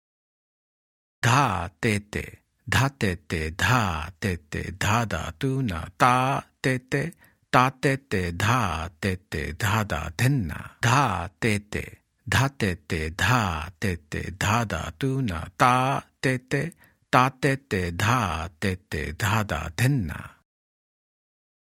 Example 7 – Spoken Twice